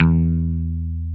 Index of /90_sSampleCDs/Roland LCDP02 Guitar and Bass/BS _Rock Bass/BS _Dan-O Bass